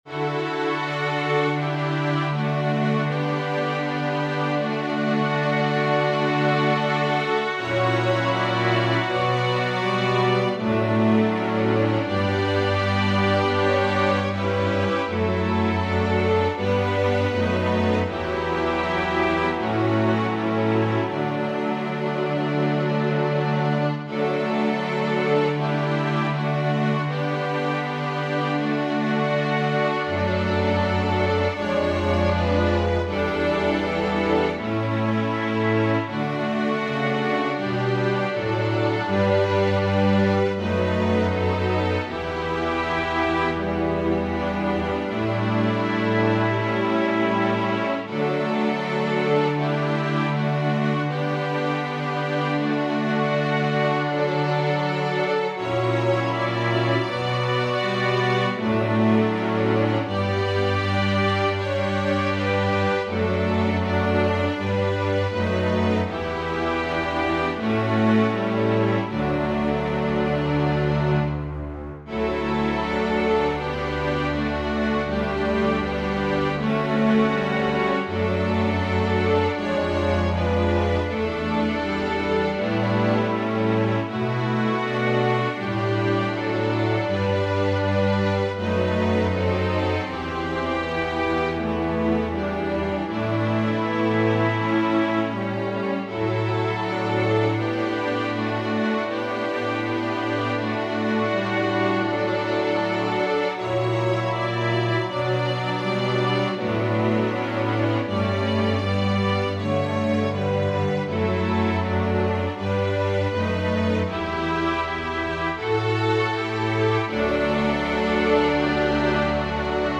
Voicing/Instrumentation: Organ/Organ Accompaniment